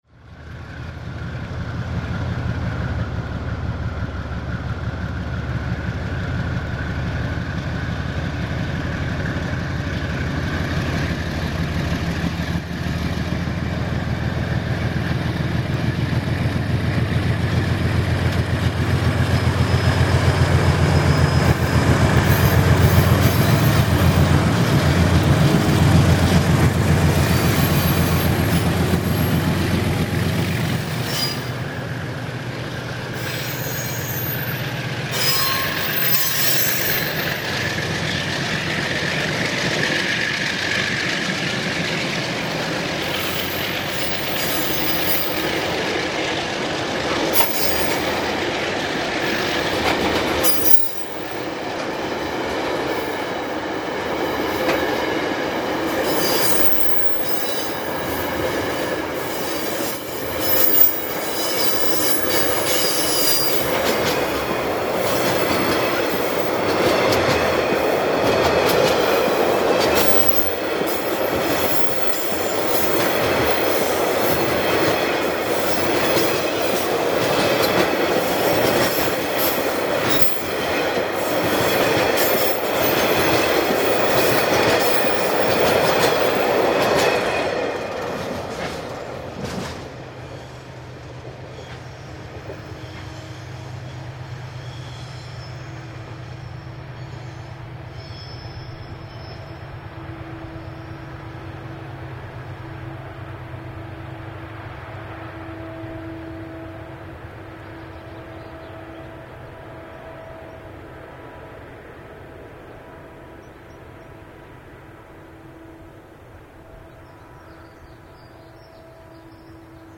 That was actually a bonus, as it crossed a loaded coal train heading down to Christchurch, so here is the sound of another pair of DX's, led by DX 5402.  What a roar!